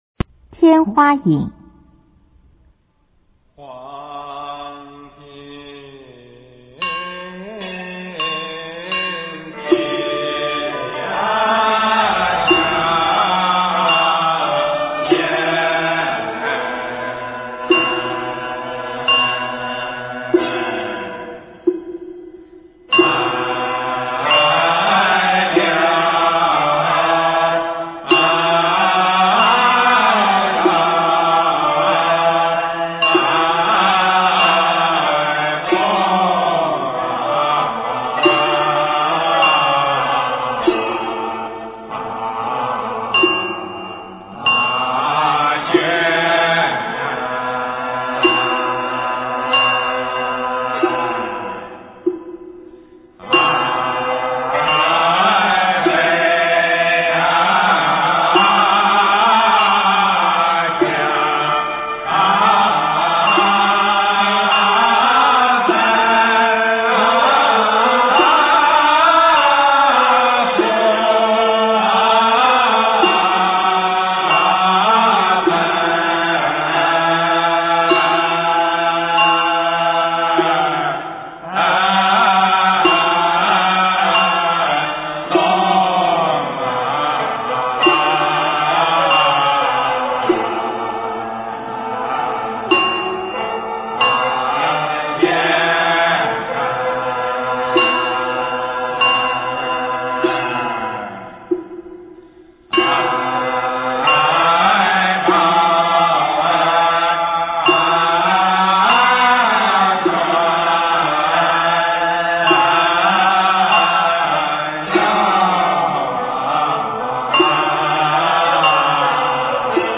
中国江南体育场馆中国有限公司 音乐 全真正韵 天花引/黄庭赞